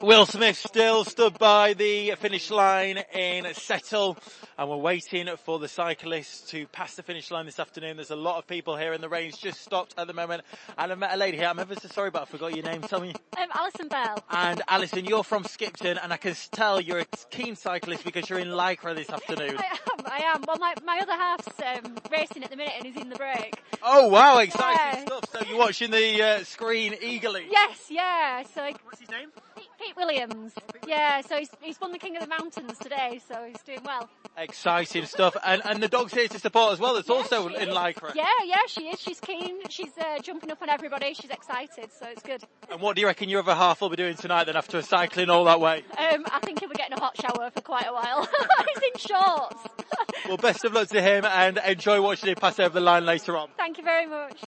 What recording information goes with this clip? Stray FM at the Tour de Yorkshire finish line in Settle